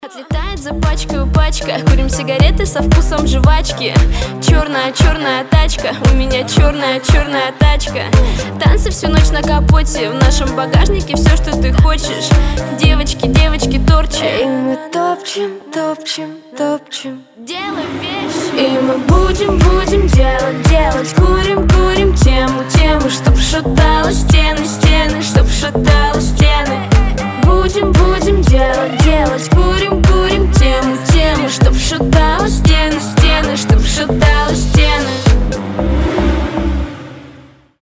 • Качество: 320, Stereo
поп
ритмичные
женский вокал
чувственные